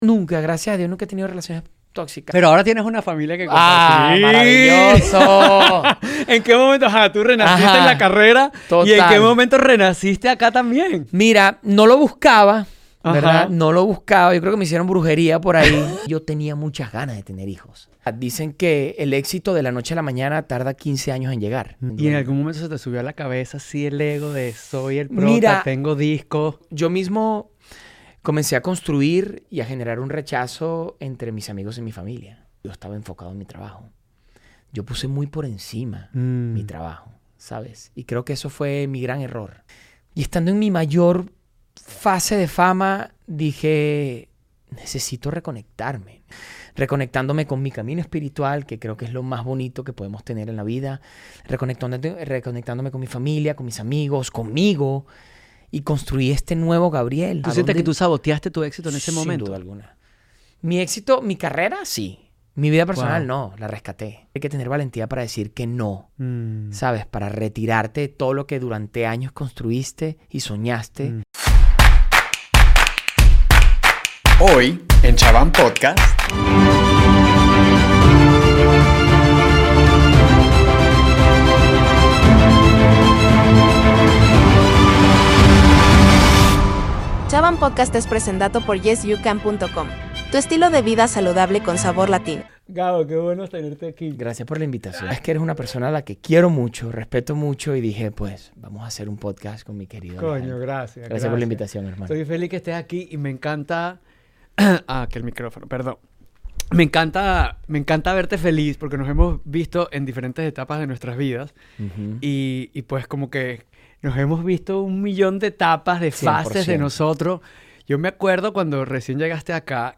En esta ocasión, tengo el honor de conversar con un talentoso y querido amigo, el actor y cantante venezolano Gabriel Coronel.